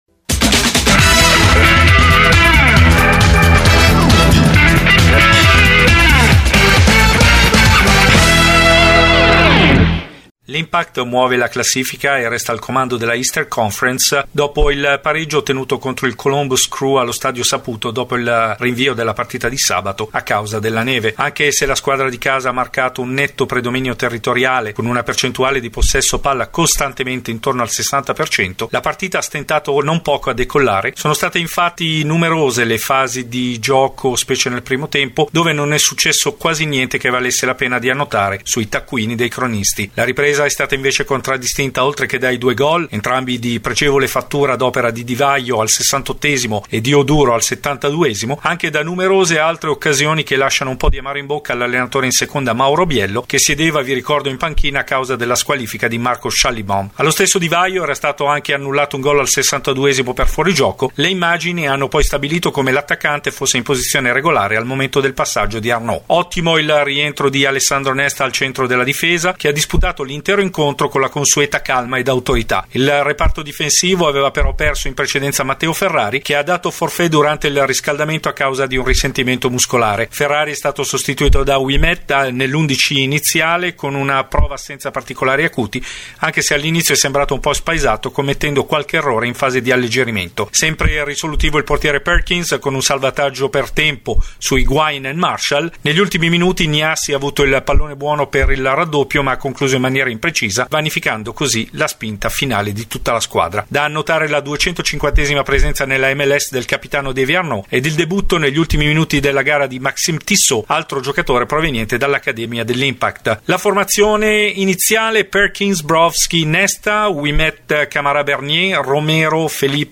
Servizio completo, interviste e classifica.